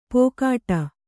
♪ pōkāṭa